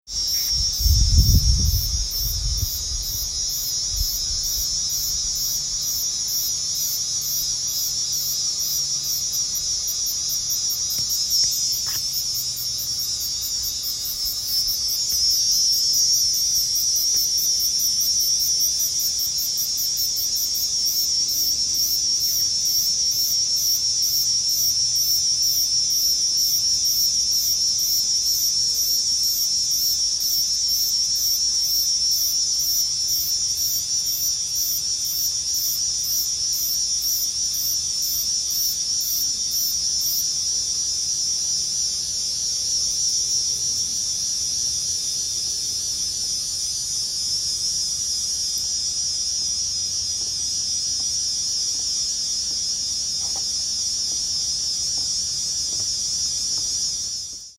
Cicadas at the Portal Lookout in the Blue Mountains, Australia.